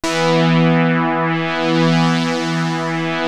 JUP.8 E4   2.wav